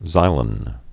(zīlən)